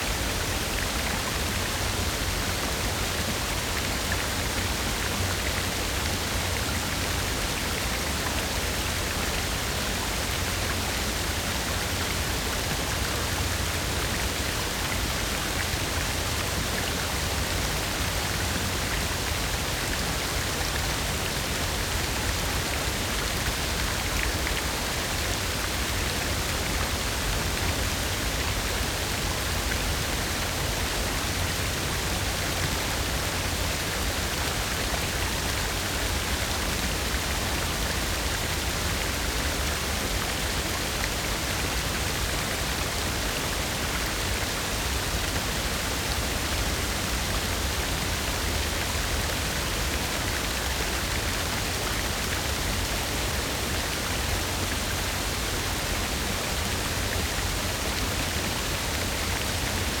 Free Fantasy SFX Pack
Waterfalls Rivers and Streams
Waterfall Loop.wav